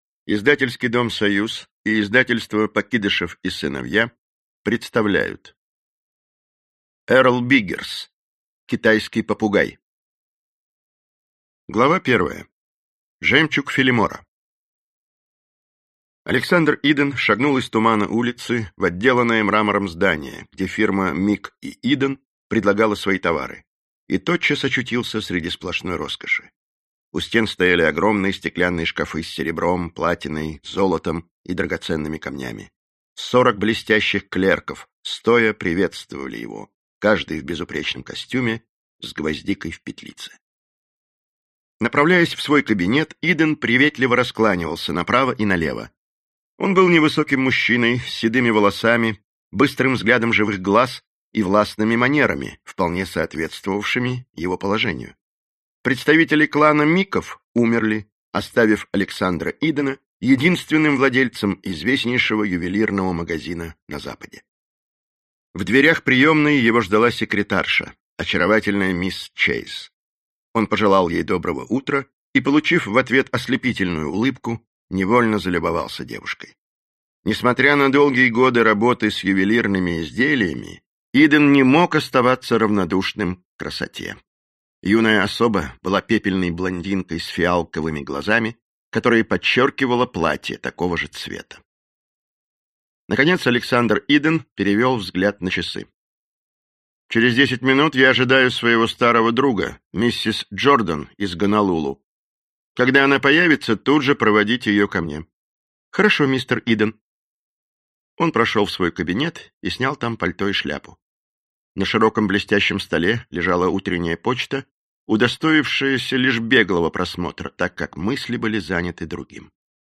Аудиокнига Китайский попугай | Библиотека аудиокниг